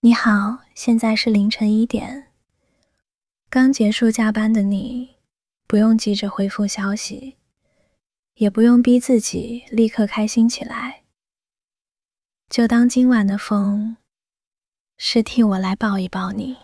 智东西上传的提示词是“一位20多岁的女性，说南方软语，声线慵懒松弛，带一点点刚睡醒的鼻音，她是深夜电台主播念稿时尾音轻放，读听众留言时会放柔语气。”
生成的音频确实声线慵懒，听起来是一位年轻女气，但说话时仍然是普通话，没有南方软语的特征。